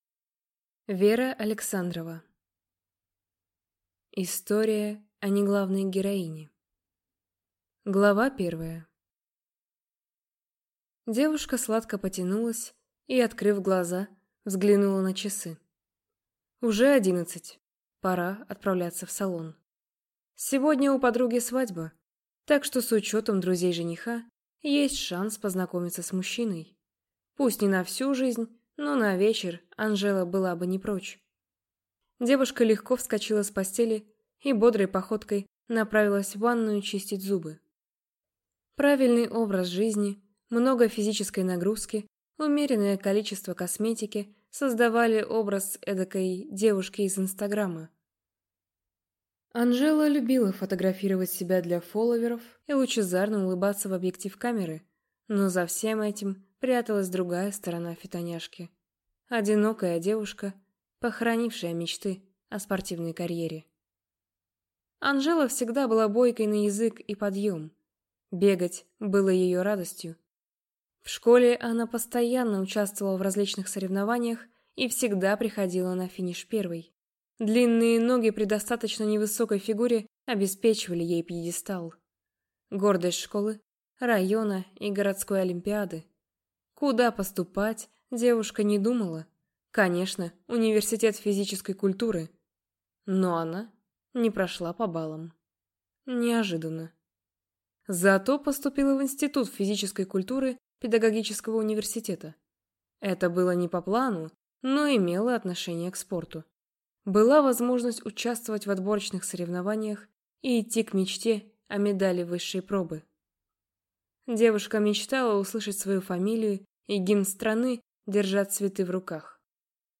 Аудиокнига История о неглавной героине | Библиотека аудиокниг